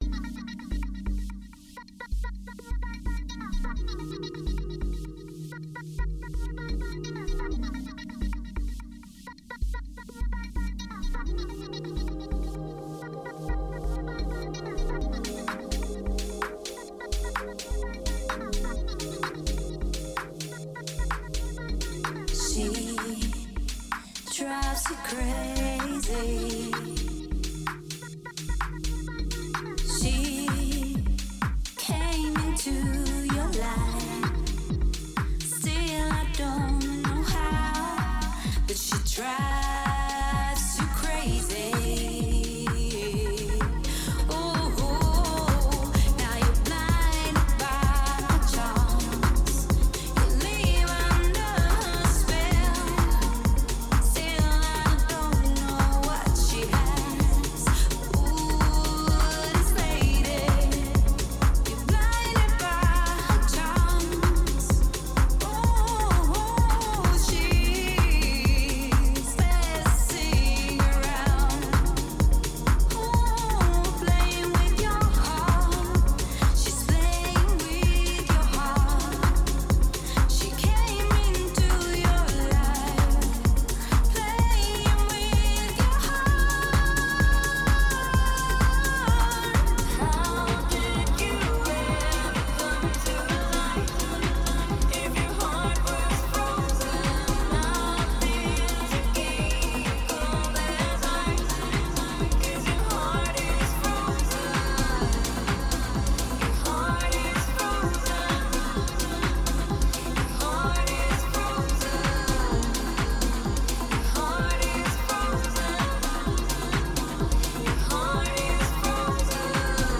is a very vocal-full house track with melancholic lyrics.
Tempo 128BPM (Allegro)
Genre Chillout House
Type Vocal Music
Mood Melancholic